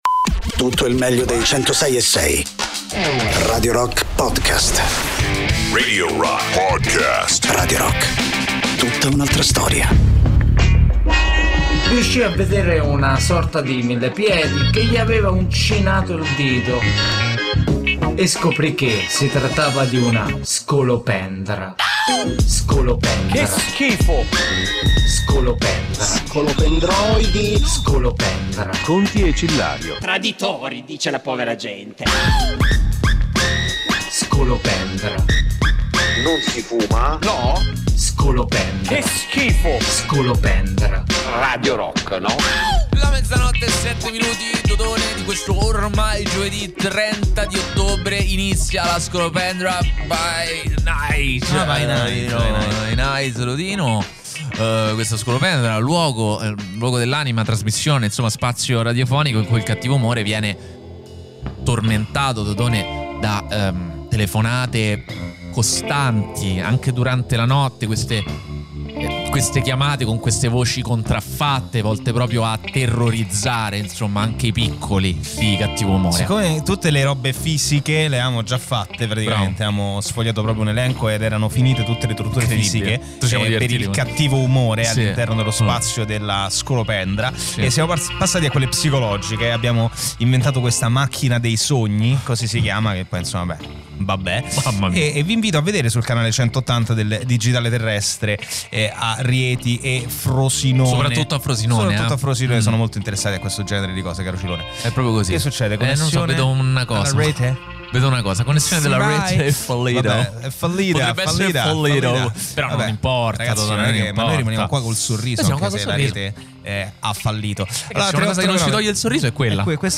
in diretta Sabato e Domenica dalle 15 alle 18